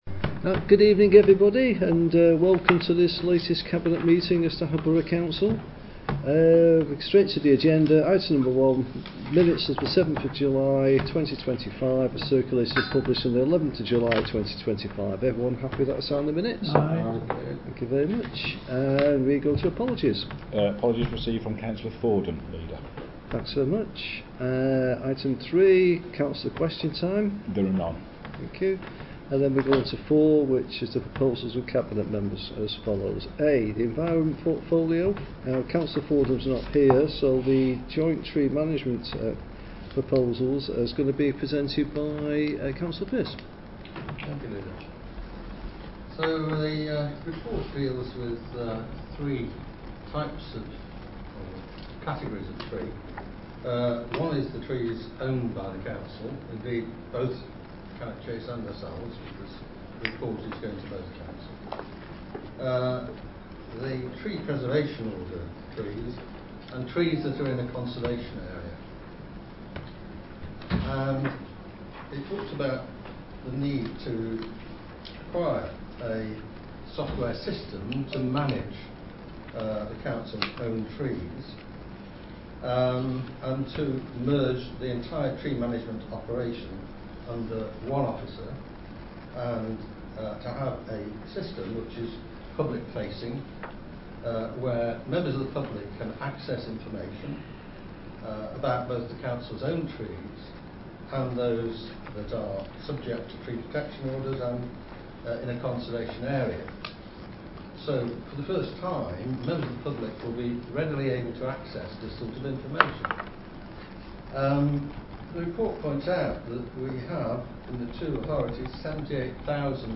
Location: Craddock Room, Civic Centre, Riverside, Stafford